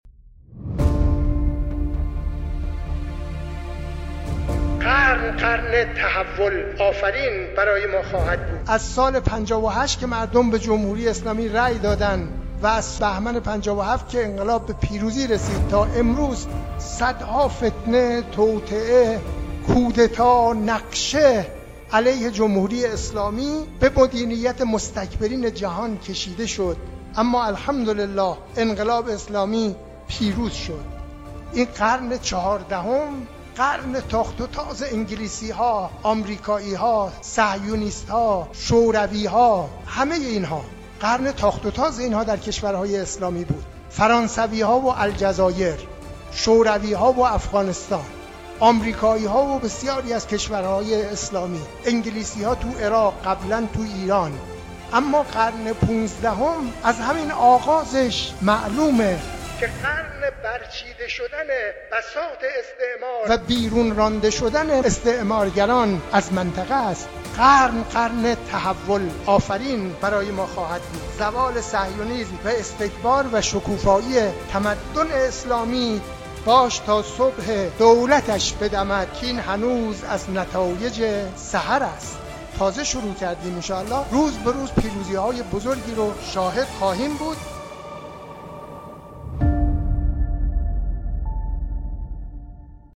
گوینده پادکست: آیت‌الله عبدالکریم عابدینی